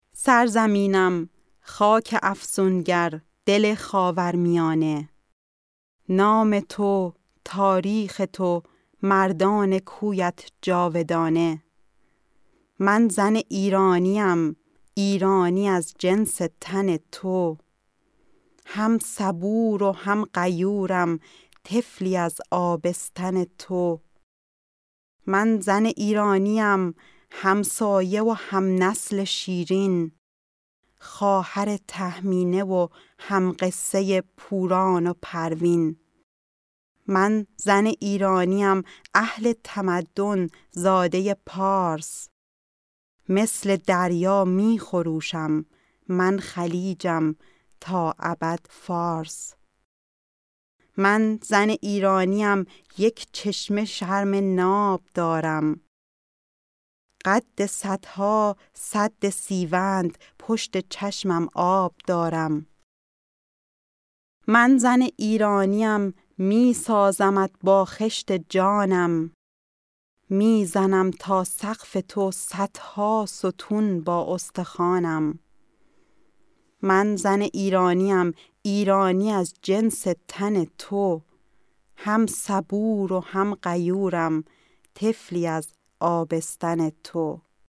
Sprechprobe: Sonstiges (Muttersprache):
04 Gedicht.mp3